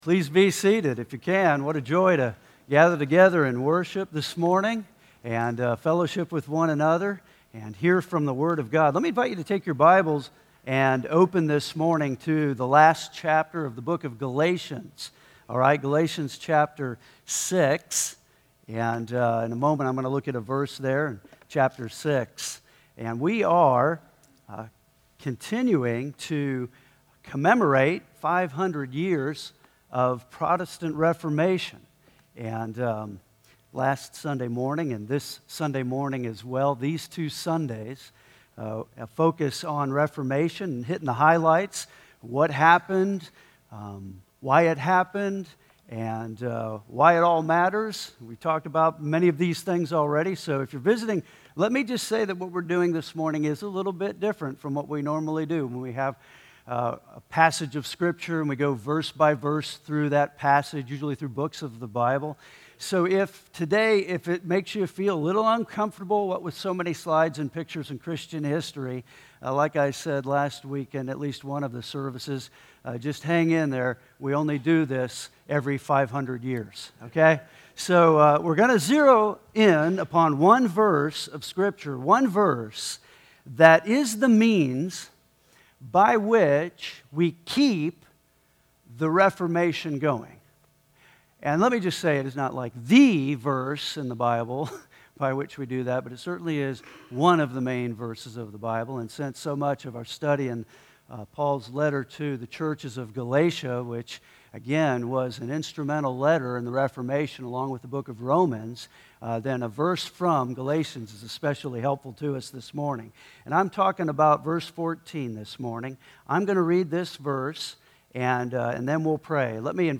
Today is Reformation Sunday.